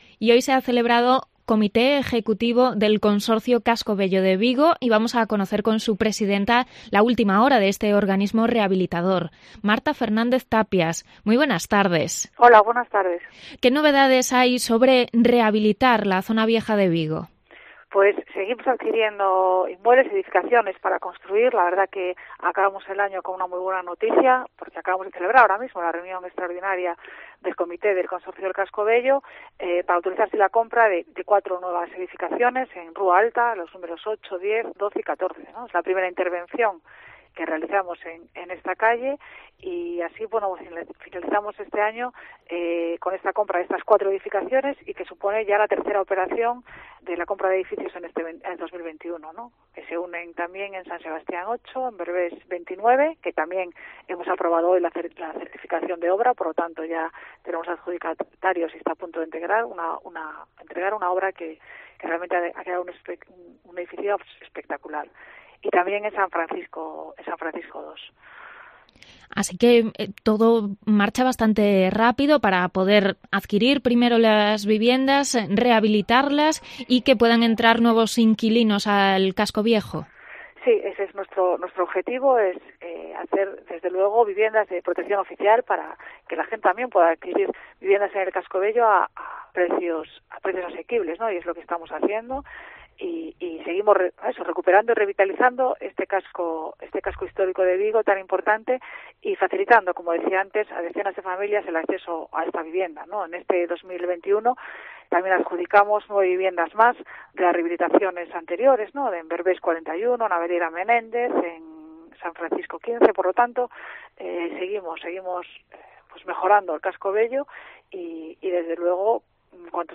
Entrevista a la delegada de la Xunta en Vigo y presidenta del Consorcio, Marta Fernández-Tapias.